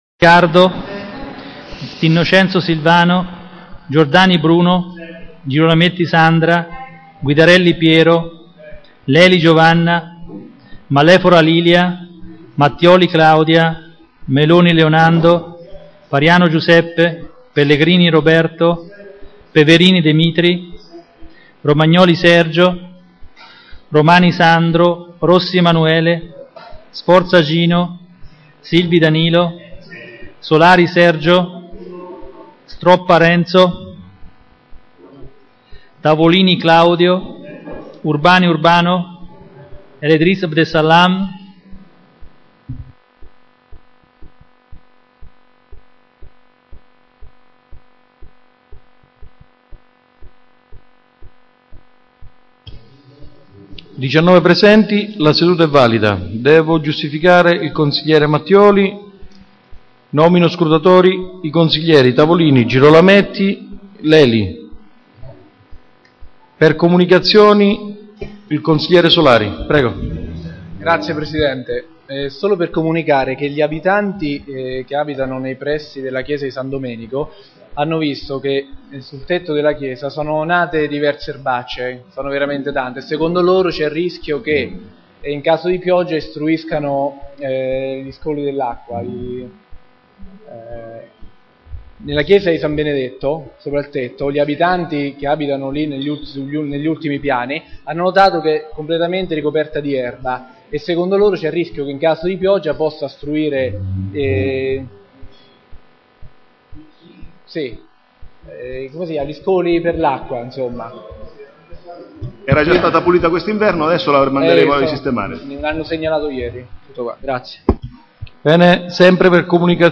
File audio della seduta